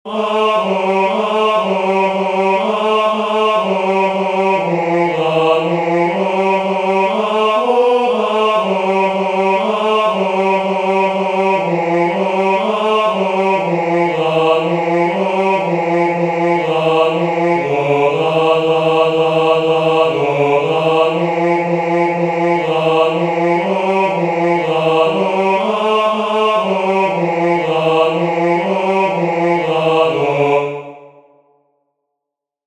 "Isti sunt qui venerunt," the third responsory verse from the second nocturn of Matins, Common of Apostles